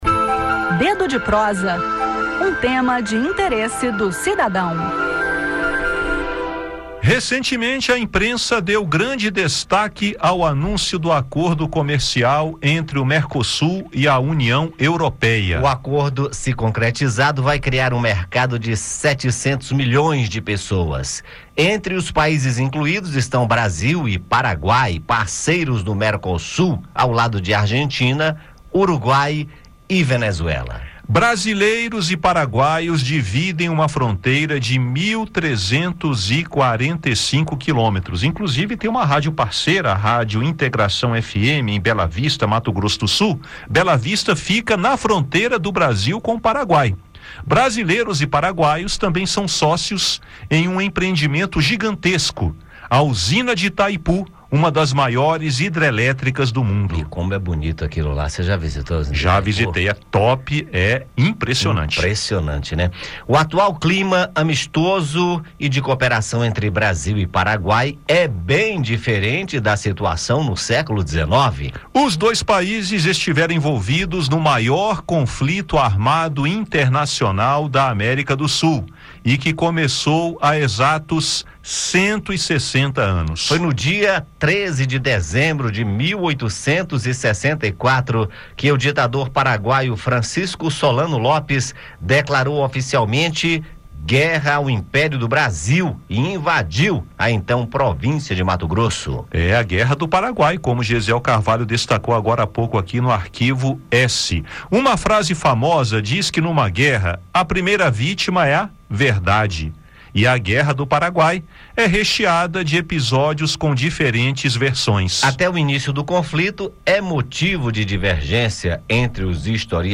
Por isso foi formada a Tríplice Aliança, que, com a ajuda da Inglaterra, venceu a guerra. Ouça mais detalhes sobre esse conflito histórico no bate-papo.